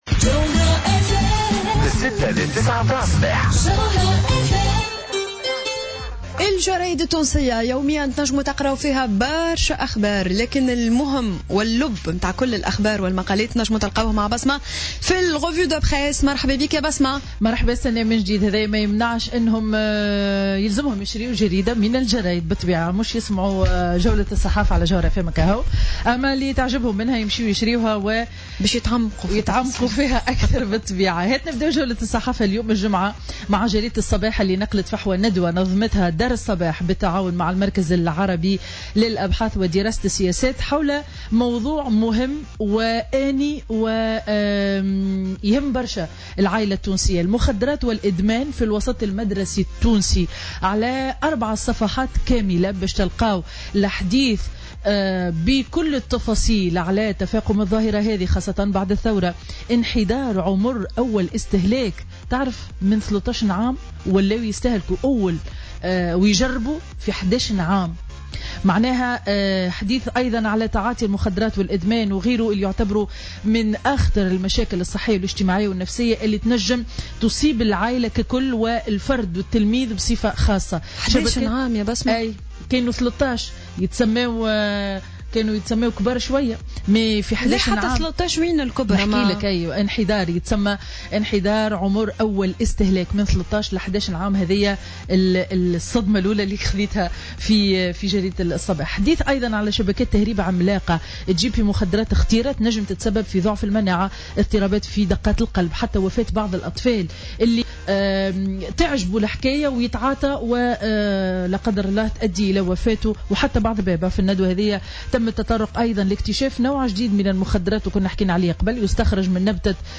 Revue de presse du vendredi 14 avril 2017